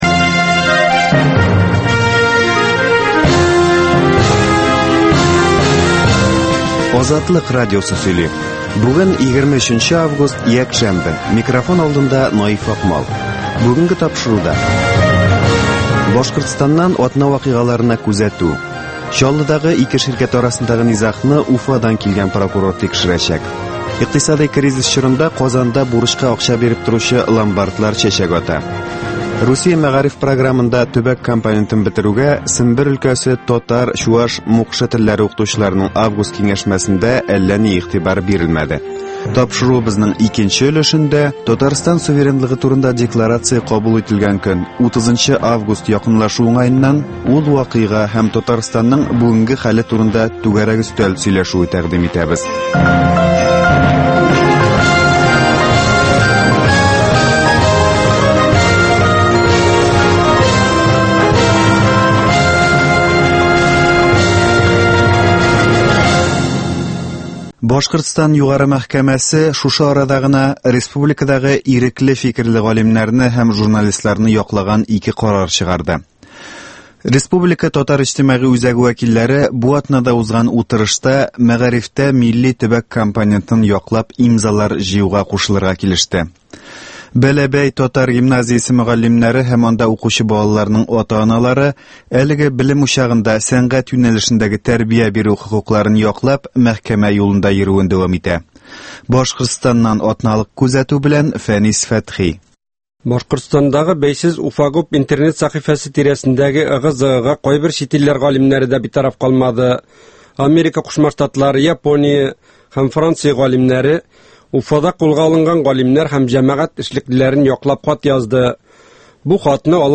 Азатлык узган атнага күз сала - башкортстаннан атналык күзәтү - татар дөньясы - түгәрәк өстәл артында сөйләшү